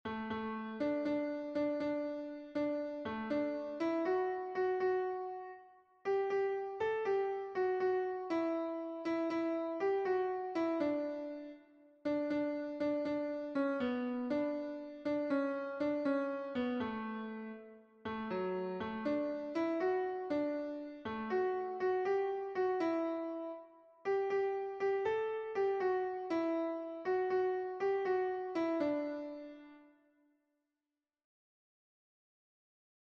Hamarreko txikia (hg) / Bost puntuko txikia (ip)